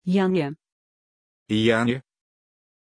Pronunciation of Janja
pronunciation-janja-ru.mp3